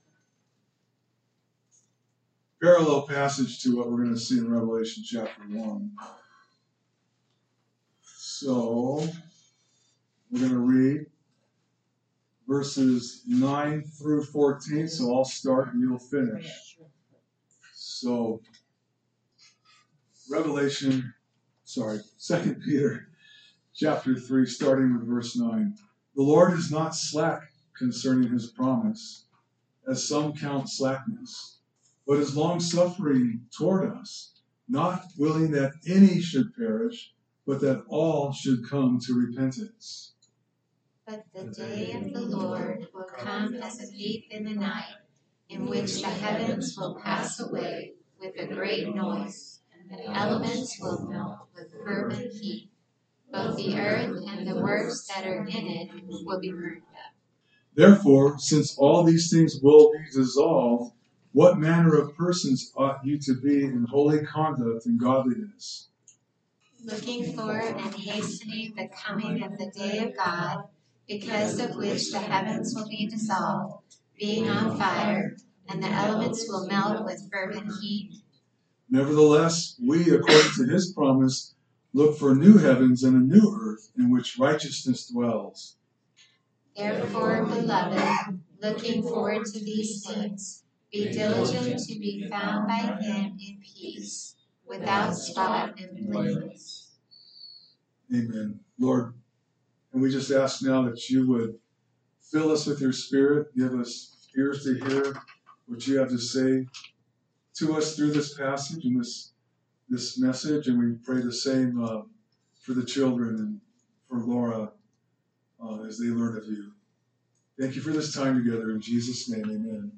A message from the series "Revelation."